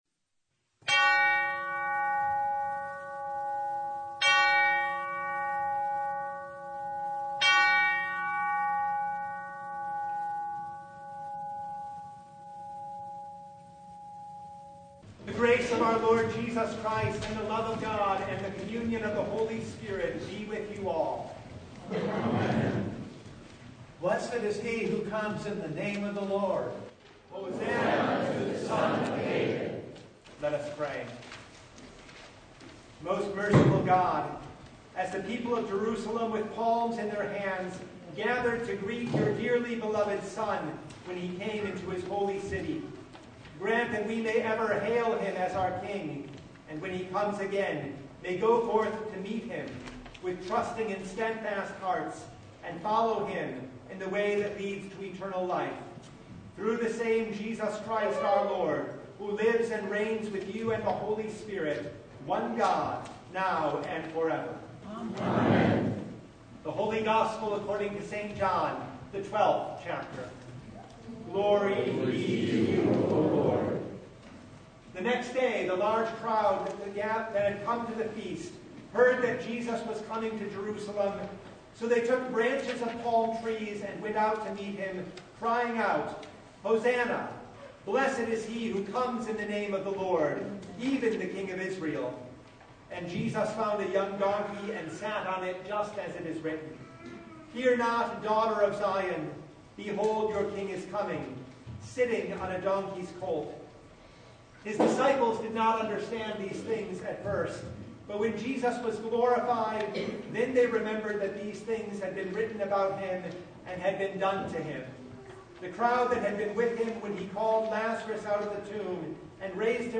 Philippians 2:5-11 Service Type: Palm Sunday As we head toward Holy Week with Jesus, we see true humility at work.